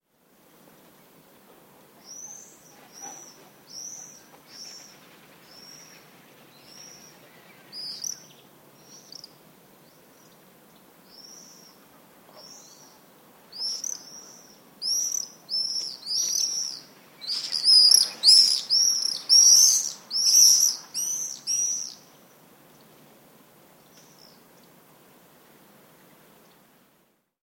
На этой странице собраны звуки, издаваемые стрижами: их звонкое щебетание, крики в полёте и другие природные голоса.
Открытое окно в доме и стрижи внутри